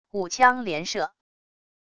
五枪连射wav音频